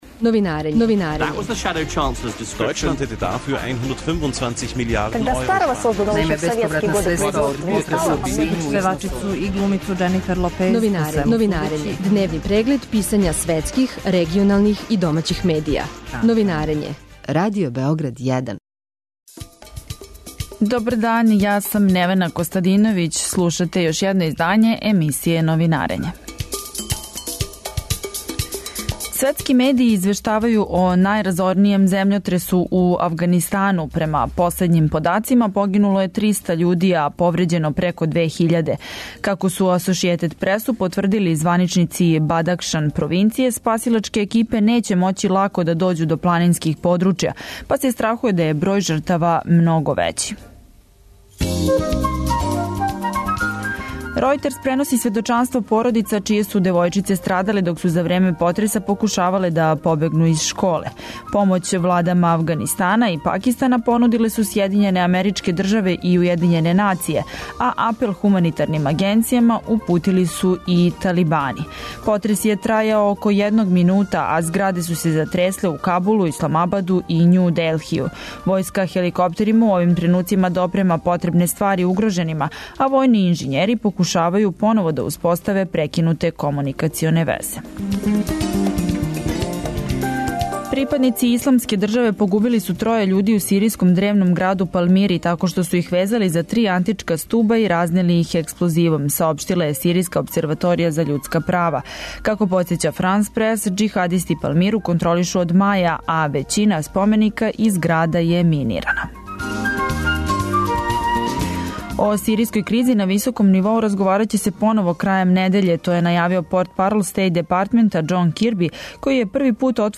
[ детаљније ] Све епизоде серијала Аудио подкаст Радио Београд 1 Ромска права у фокусу Брисела Вести из света спорта Хумористичка емисија Хумористичка емисија Хумористичка емисија